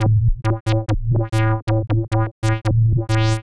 Index of /musicradar/uk-garage-samples/136bpm Lines n Loops/Synths